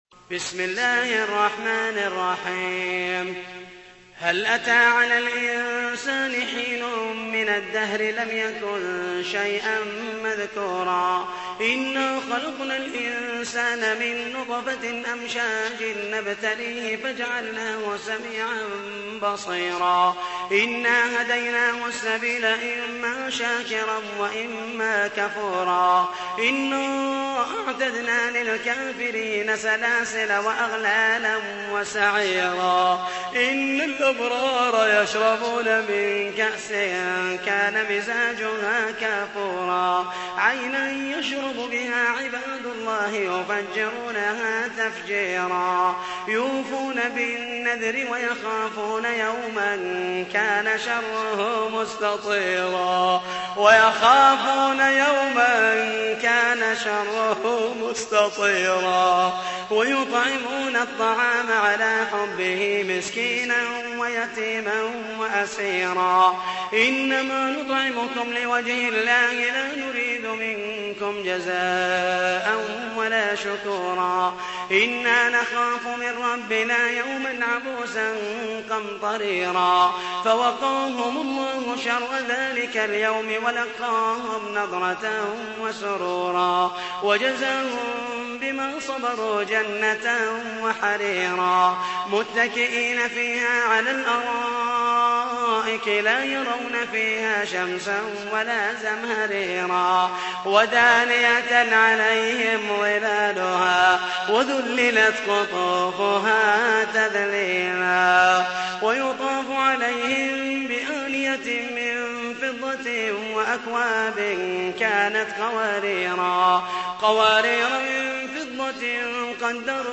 تحميل : 76. سورة الإنسان / القارئ محمد المحيسني / القرآن الكريم / موقع يا حسين